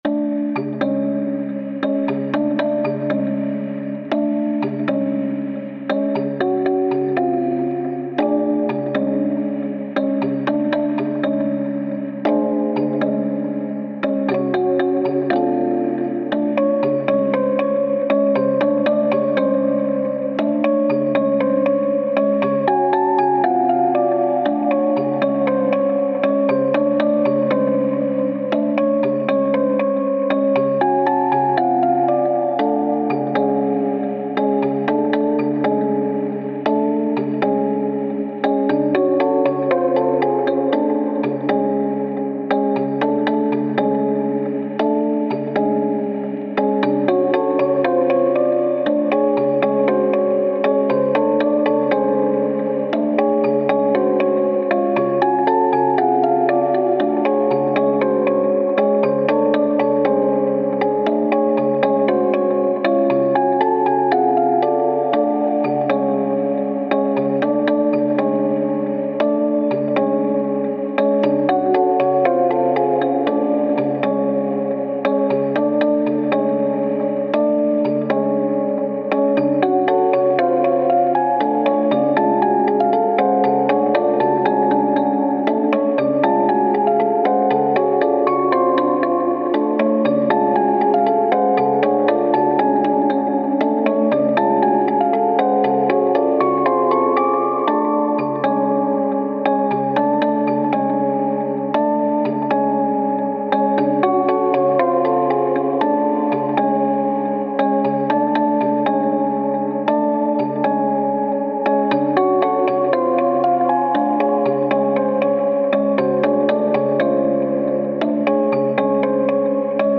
アタック音と残響が特徴的な加工したオルゴールの音色で怪しい雰囲気が漂っている。 ダブルハーモニックスケールを使用しており
タグ: 不気味/奇妙 怪しい 暗い コメント: 怪しい取引を持ちかける商人をイメージした楽曲。